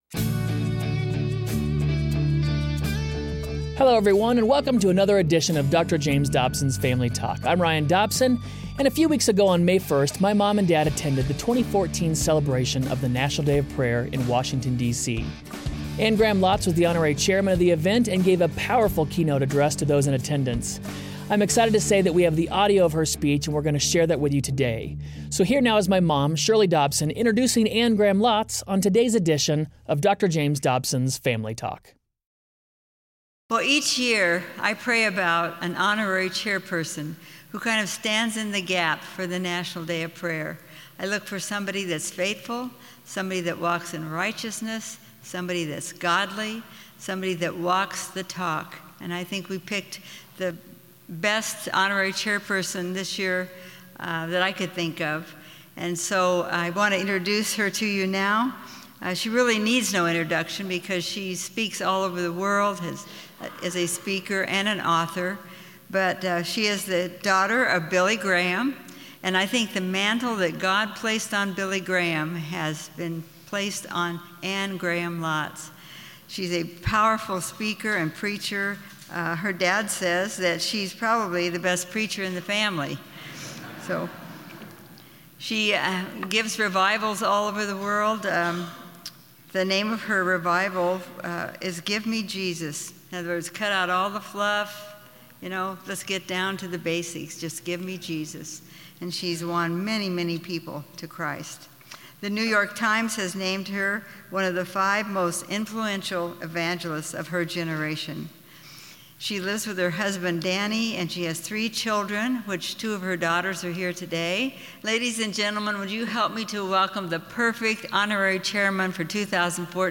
What is it going to take to get America back on the right track? On the next edition of Family Talk, Anne Graham Lotz pleads the case for America to turn back to God at the National Day of Prayer 2014.